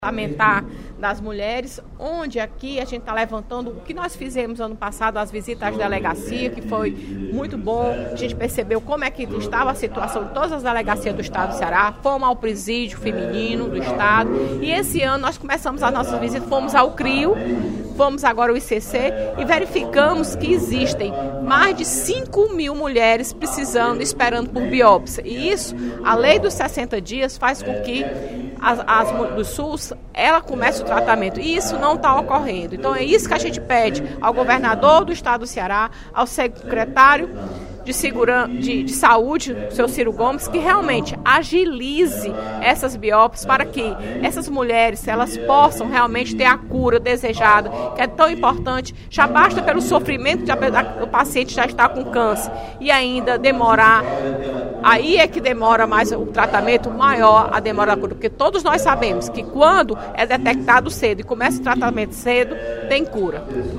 A deputada Fernanda Pessoa (PR) adiantou, no primeiro expediente da sessão plenária desta sexta-feira (14/02), que a Frente Parlamentar em Defesa dos Direitos da Mulher está visitando instituições que trabalham no tratamento de câncer feminino, para conhecer as condições de terapia dos pacientes.